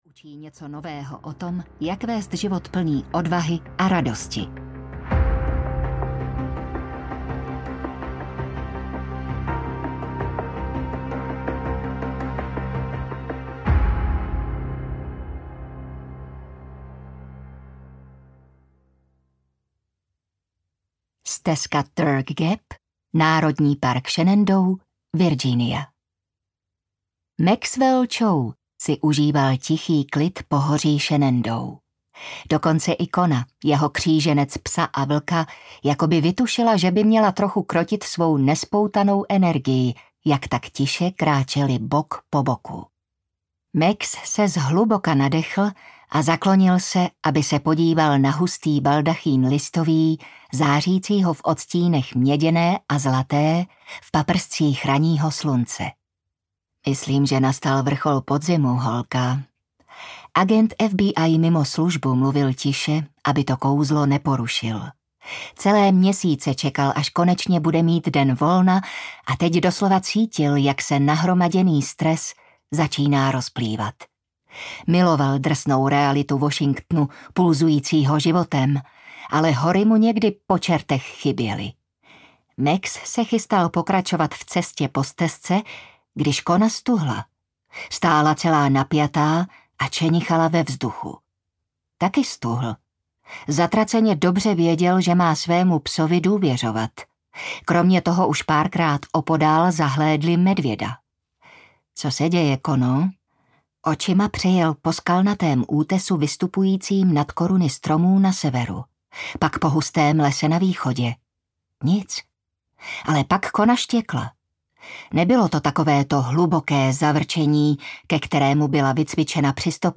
Pohřbení audiokniha
Ukázka z knihy